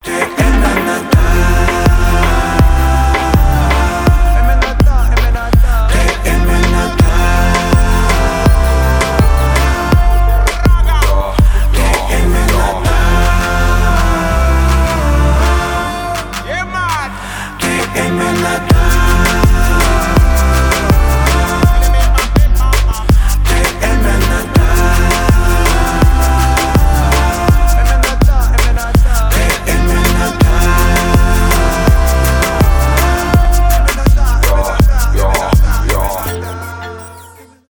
хип-хоп , реггетон
танцевальные